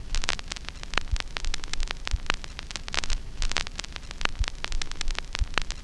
RECORD NOISE.wav